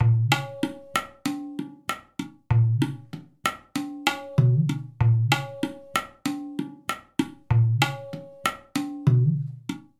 塔布拉舞曲3 96bpm
标签： 96 bpm Ethnic Loops Tabla Loops 1.68 MB wav Key : Unknown
声道立体声